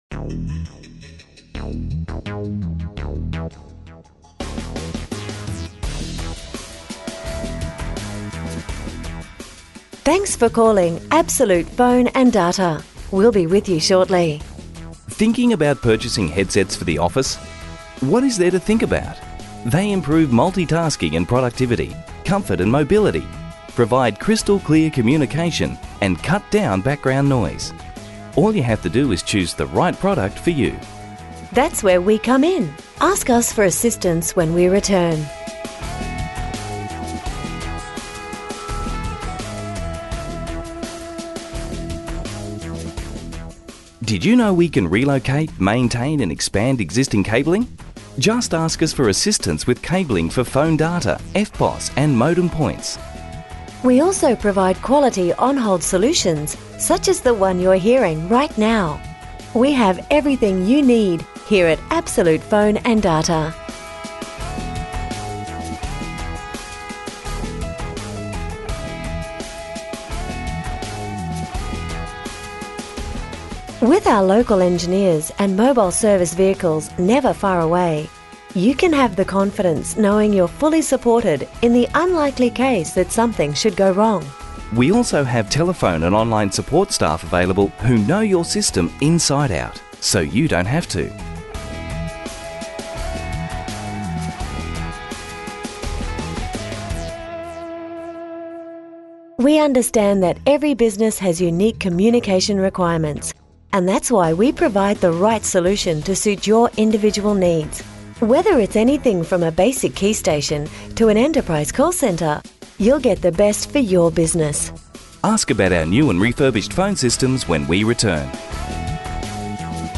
Message on Hold Sample 1
Absolute_Phone_and_Data_ONHOLD_Message.mp3